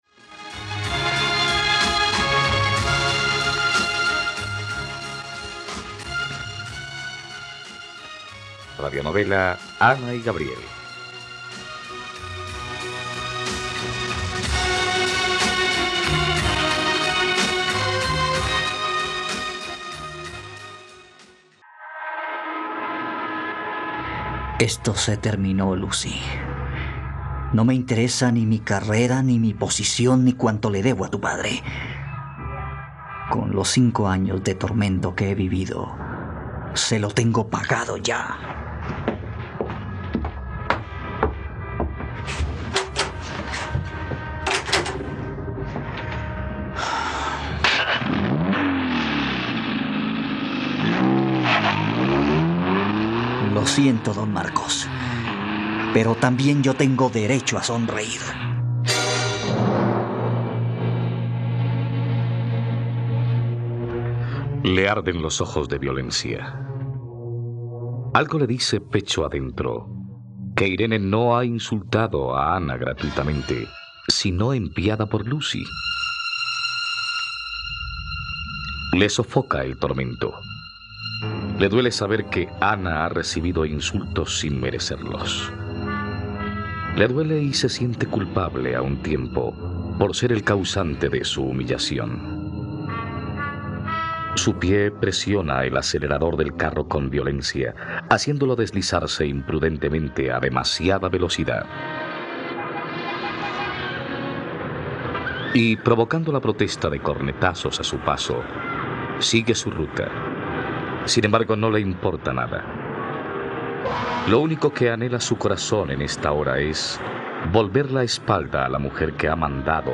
..Radionovela. Escucha ahora el capítulo 41 de la historia de amor de Ana y Gabriel en la plataforma de streaming de los colombianos: RTVCPlay.